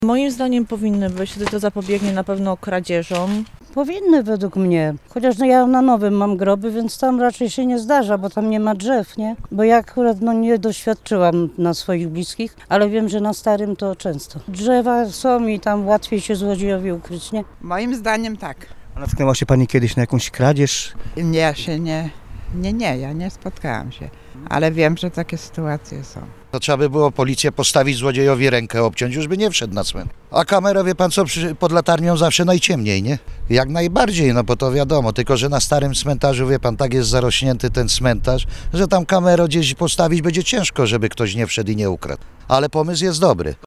Mieszkańcom pomysł się podoba. – Kamery poprawią bezpieczeństwo – oceniają zielonogórzanie: